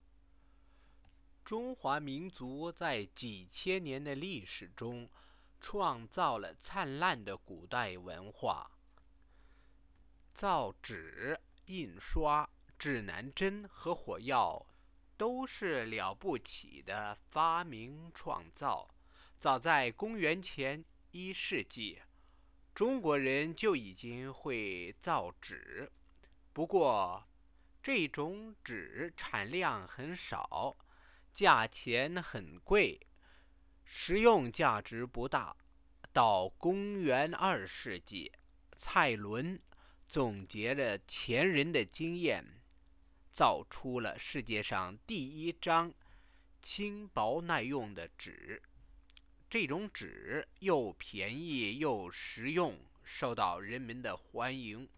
Click "Listen" to hear the sentences spoken Questions (Place the mouse on the questions to view the answers)